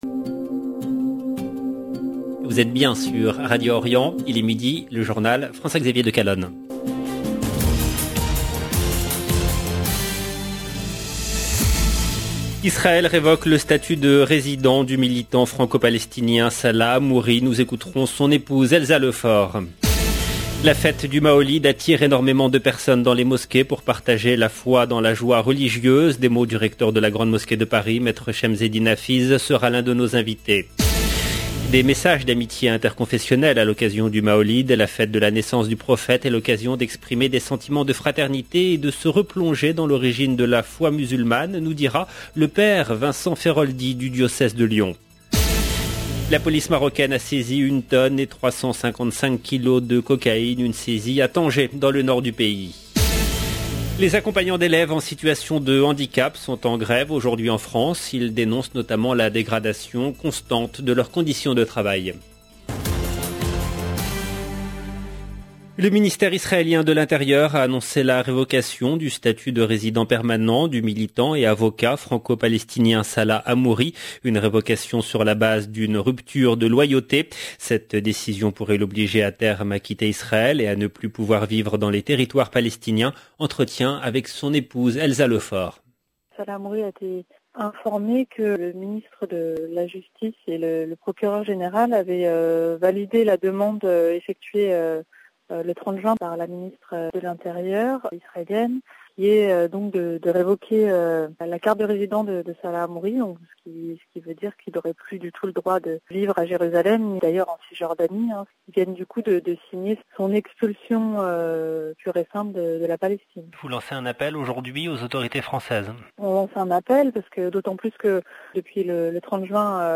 LE JOURNAL DE 12H EN LANGUE FRANCAISE DU 19/10/2021